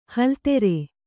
♪ haltere